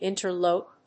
音節in・ter・lope 発音記号・読み方
/ìnṭɚlóʊp(米国英語), íntəl`əʊp(英国英語)/